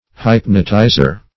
Hyp"no*ti`zer